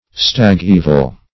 Search Result for " stag-evil" : The Collaborative International Dictionary of English v.0.48: Stag-evil \Stag"-e`vil\ (st[a^]g"[=e]`v'l), n. (Far.) A kind of palsy affecting the jaw of a horse.